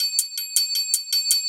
Ride 04.wav